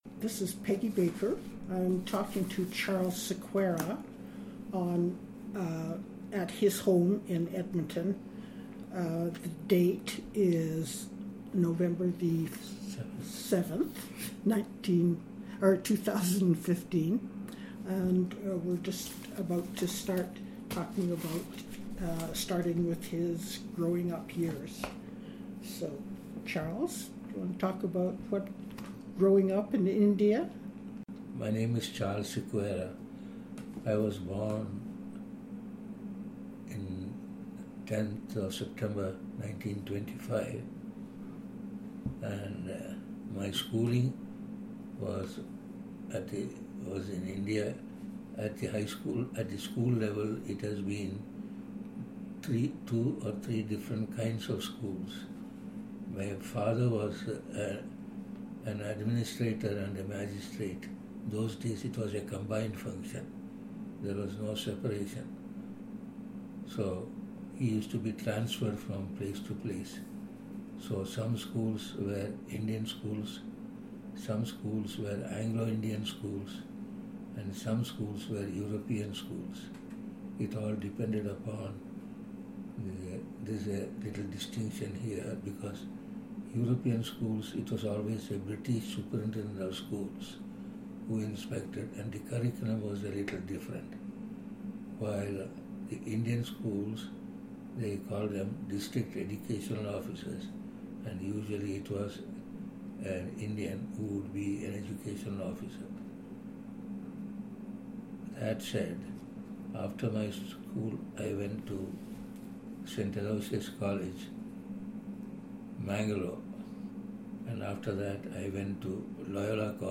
Audio interview and obituary,